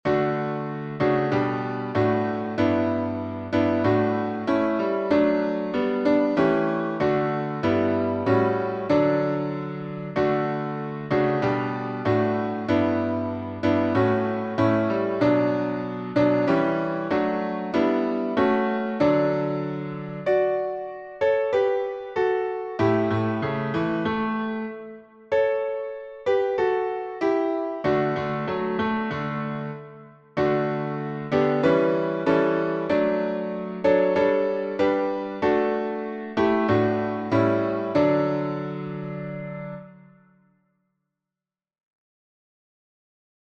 Key signature: D major (2 sharps) Time signature: 4/4